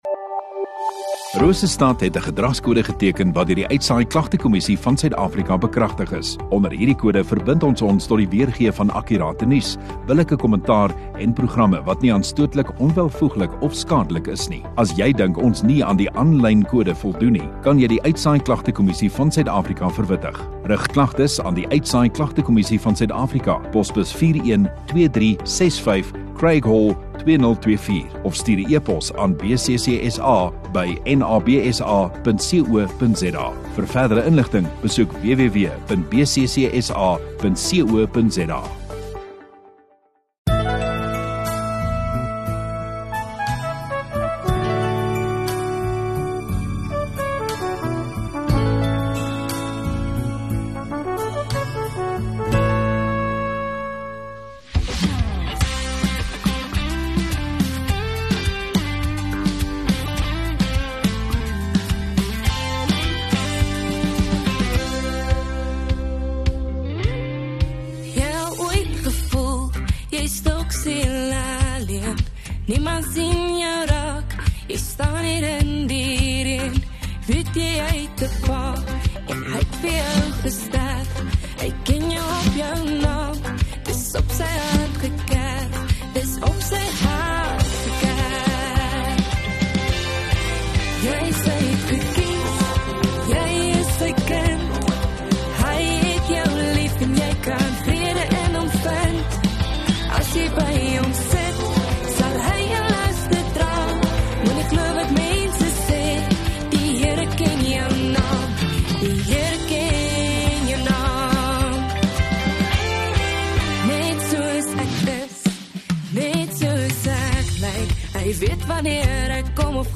28 Dec Saterdag Oggenddiens